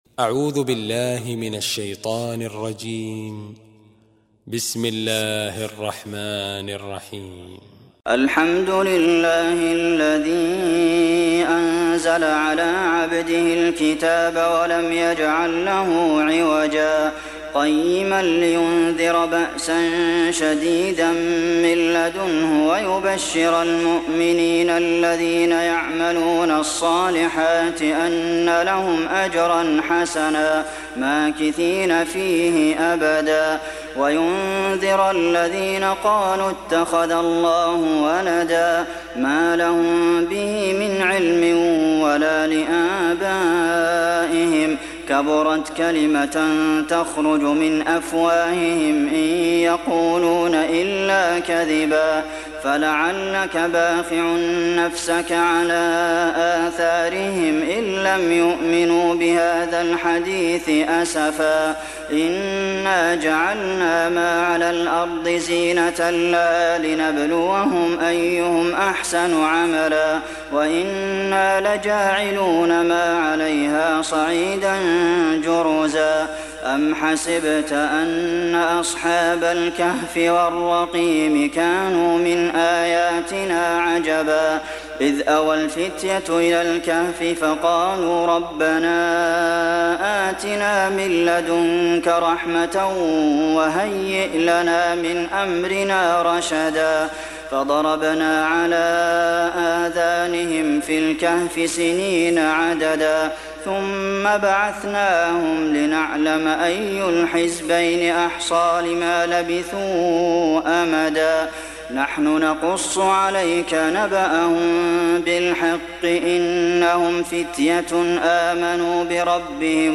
Surat Al Kahf Download mp3 Abdulmohsen Al Qasim Riwayat Hafs dari Asim, Download Quran dan mendengarkan mp3 tautan langsung penuh